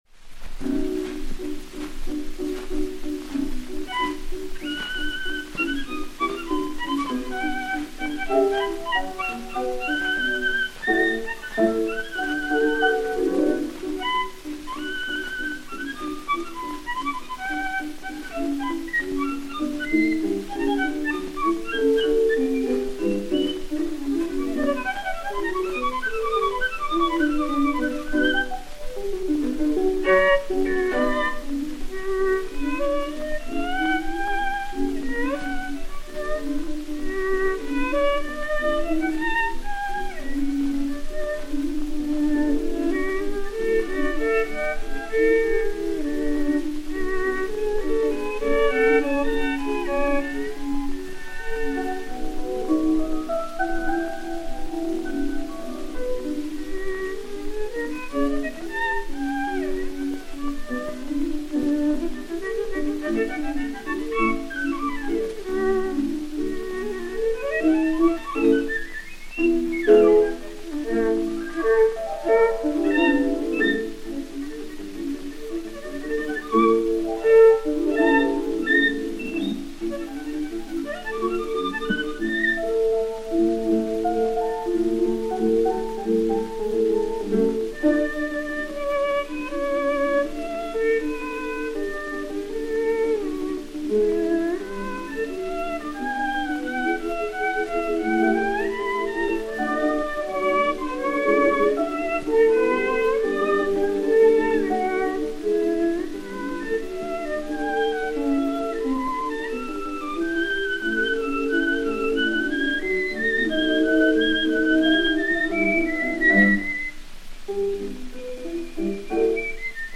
(violon) et Piano
Pathé saphir 80 tours n° 9552, mat. 6279 et 6280, enr. vers 1920